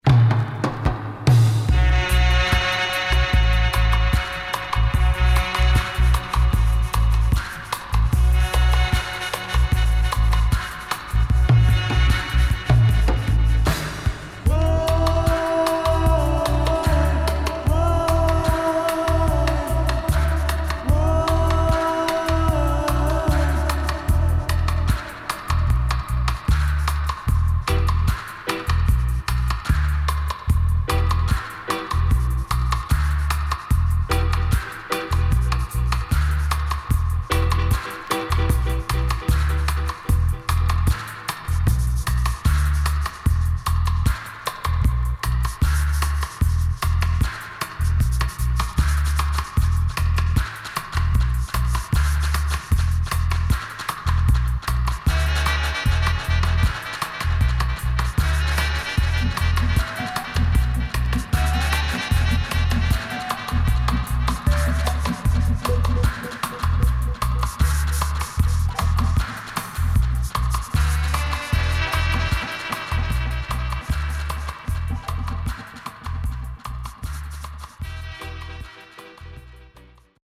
Great Roots Vocal & Dubwise
SIDE A:所々チリノイズがあり、少しプチノイズ入ります。